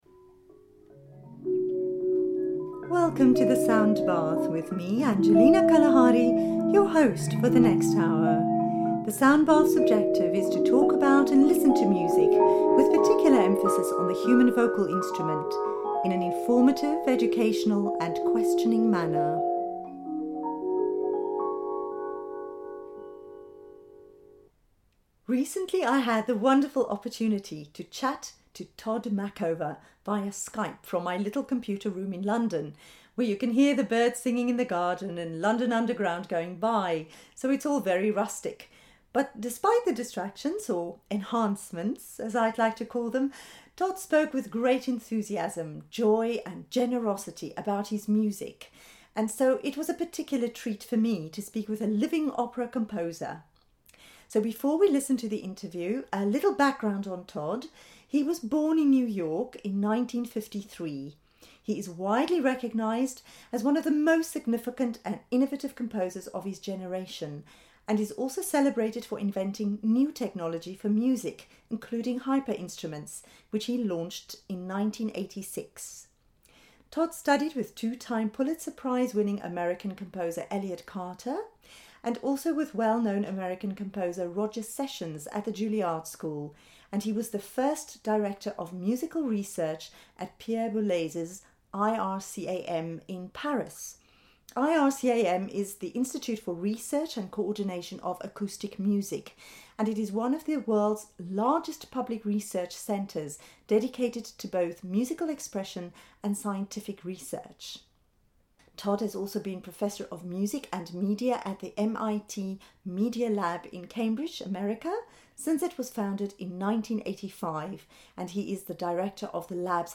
The Sound Bath’s radio interview with Tod Machover
todmachover-soundbath-interview.mp3